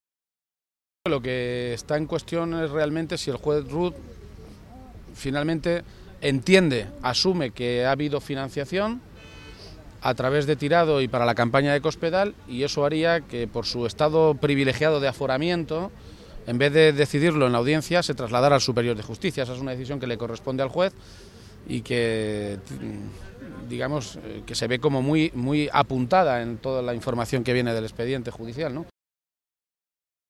García-Page se pronunciaba de esta maneta esta mañana, en Toledo, a peguntas de los medios de comunicación, horas antes de que declare como testigo en la Audiencia Nacional en el marco de las investigaciones de los llamados “papeles de Bárcenas”.
Cortes de audio de la rueda de prensa